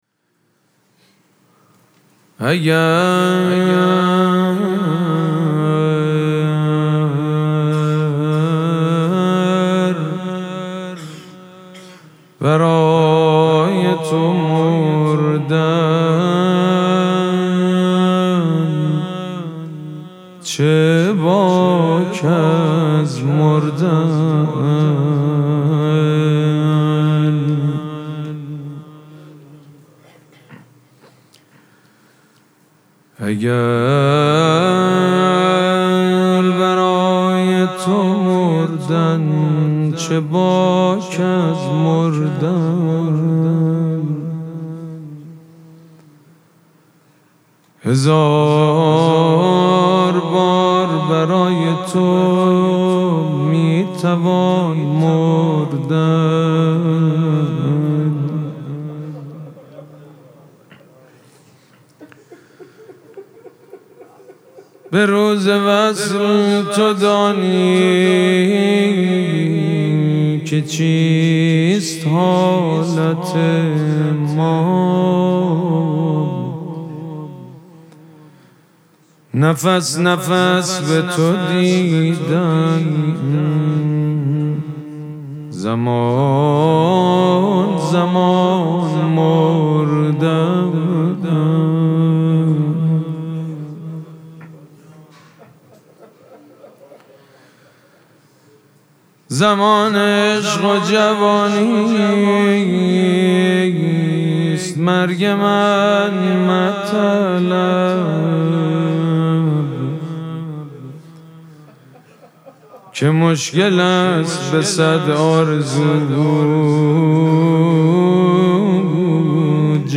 مراسم مناجات شب چهارم ماه مبارک رمضان
شعر خوانی
مداح
حاج سید مجید بنی فاطمه